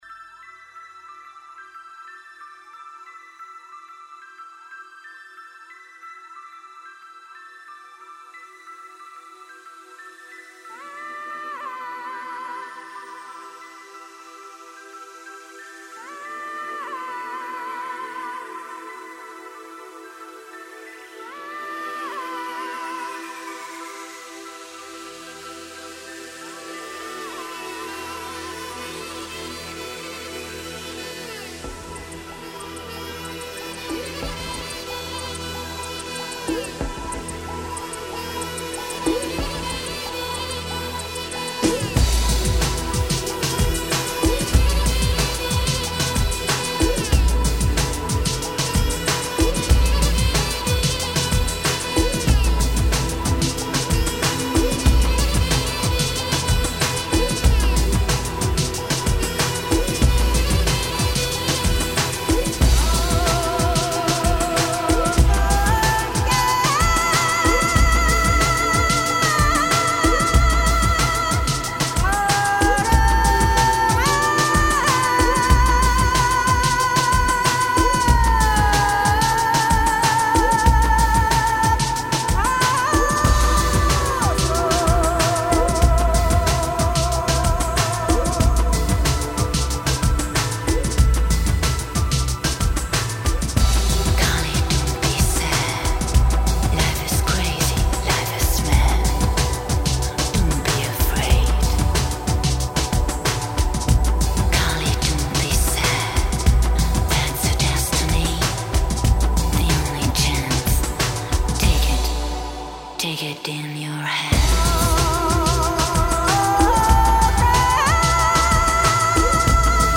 风格:发烧音乐 新音乐 迷幻